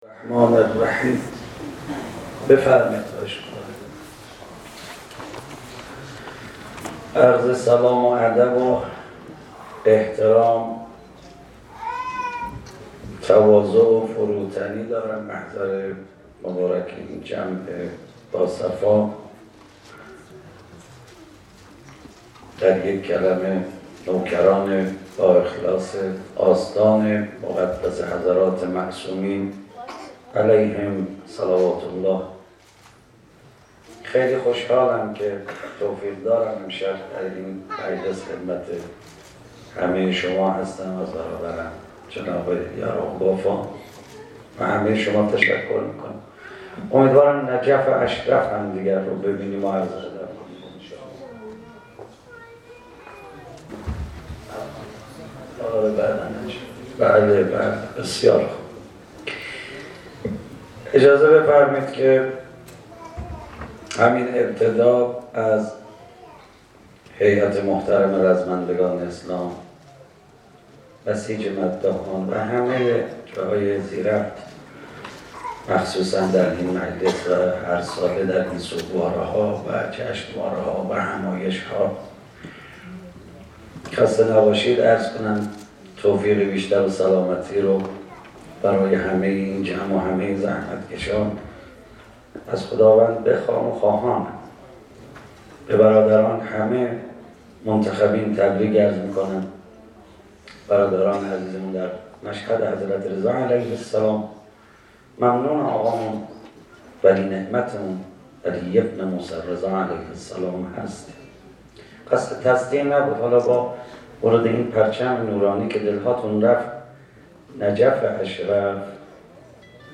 صوت این روضه‌خوانی را می‌شنوید: